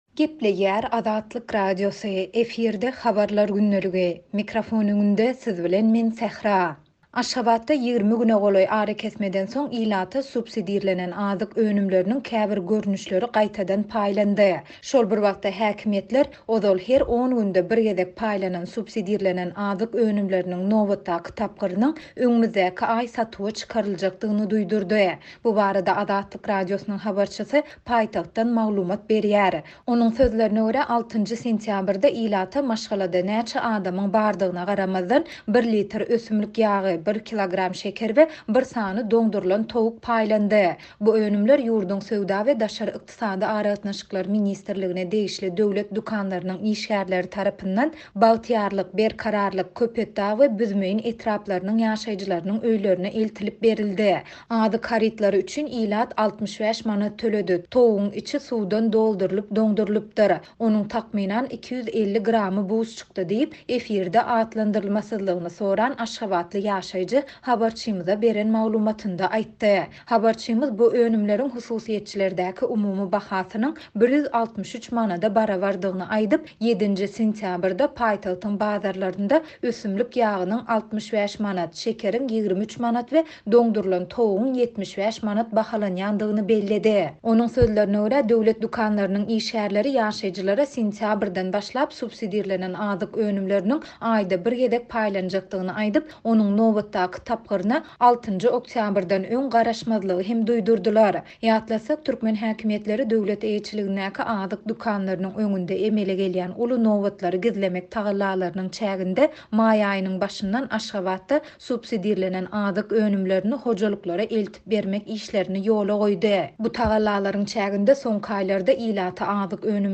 Bu barada Azatlyk Radiosynyň habarçysy paýtagtdan maglumat berýär.